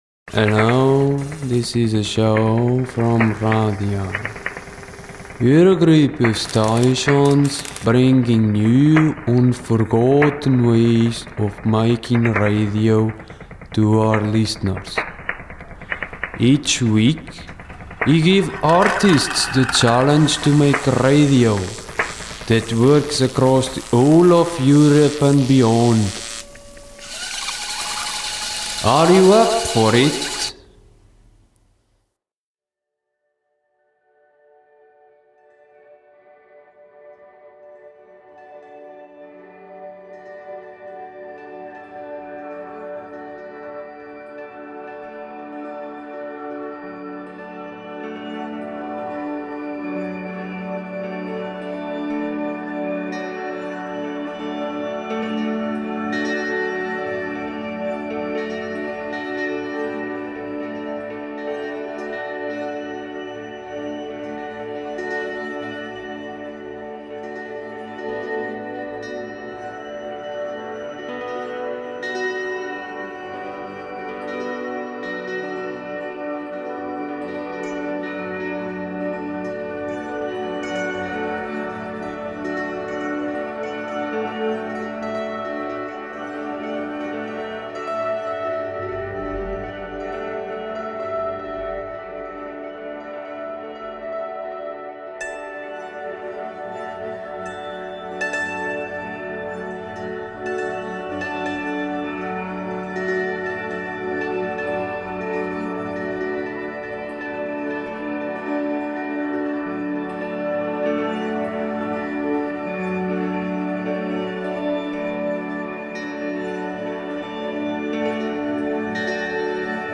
(with Radia intro and outro audio). "Interval Signals” are short pieces of musical phrasing, a sound effect or recorded speech that shortwave radio stations use to “introduce themselves” when broadcasting.
Three Rules of Discipline and Eight Points for Attention (named for the title of China radio station’s Voice of the Strait’s highly recognizable interval signal) is a work that restructures interval signals from eight different countries into miniature compositions played on instruments and in styles that are significant departures from their original form.